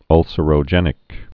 (ŭlsə-rō-jĕnĭk)